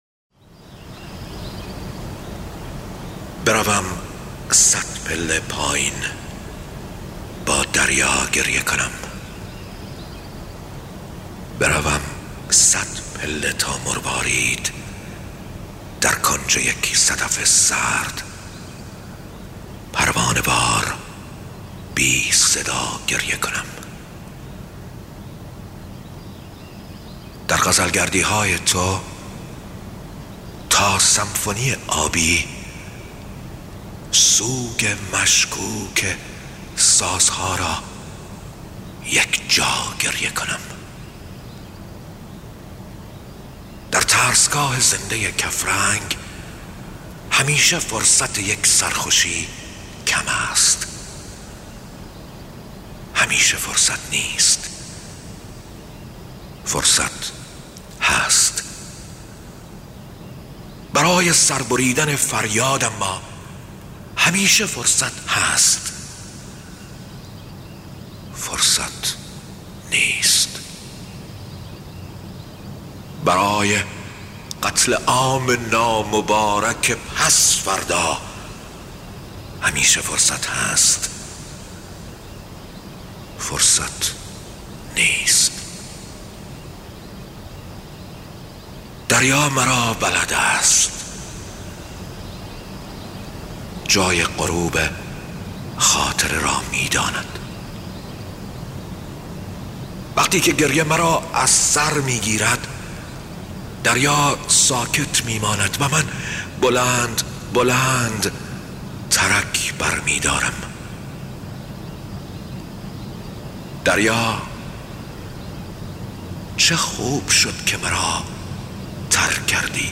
دانلود دکلمه صدپله با صدای شهیار قنبری
گوینده :   [شهیار قنبری]